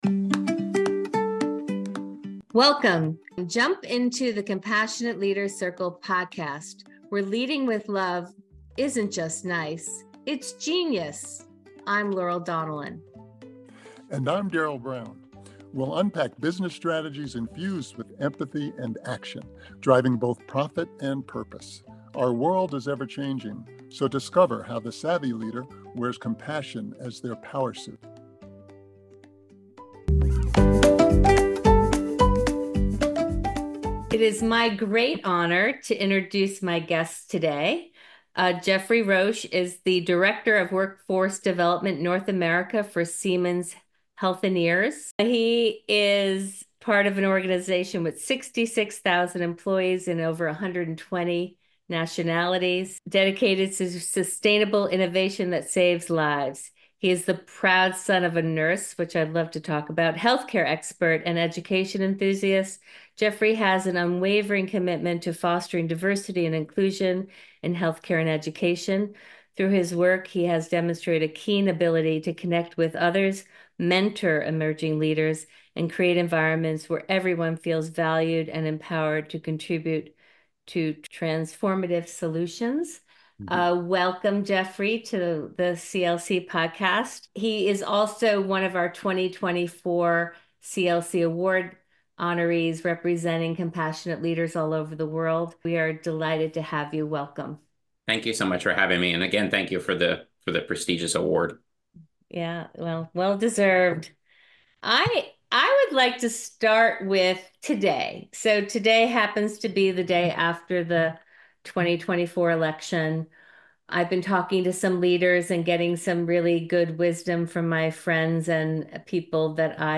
In this candid discussion